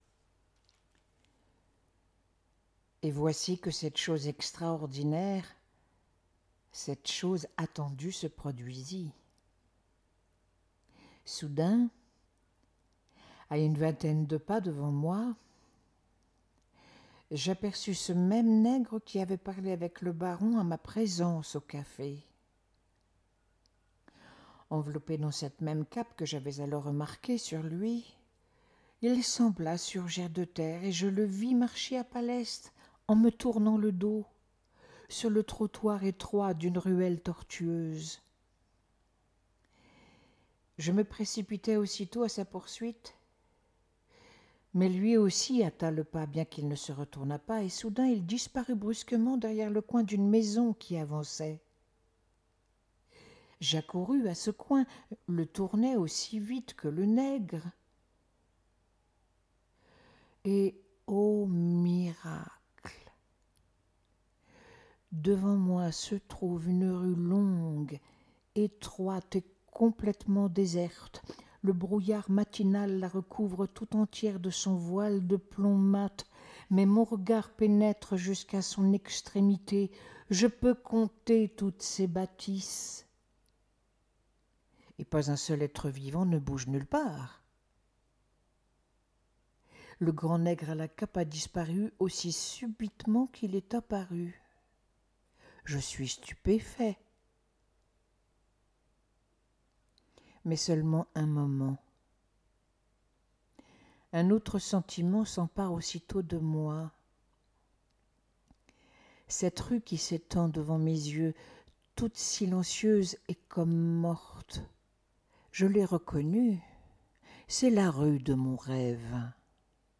Un rêve de Tourguéniev - Récit lu